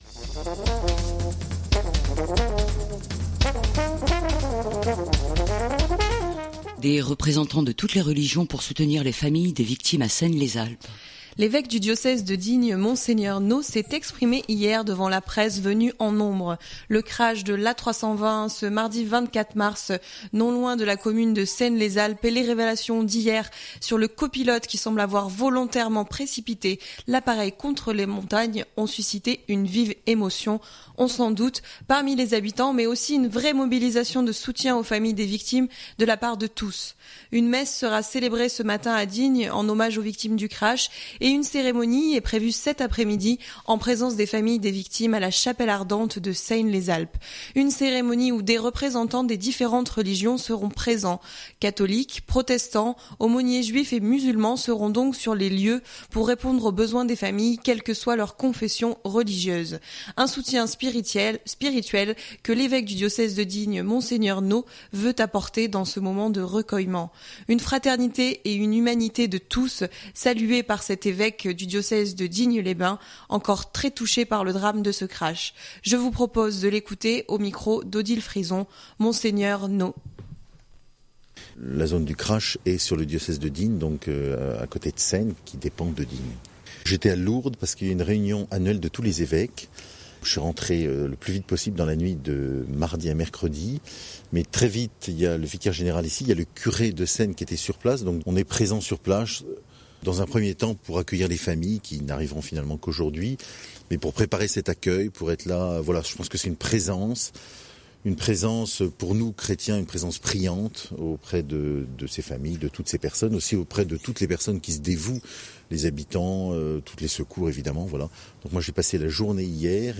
L’Evêque du diocèse de Digne, Monseigneur Nault s’est exprimé hier devant la presse venue en nombre.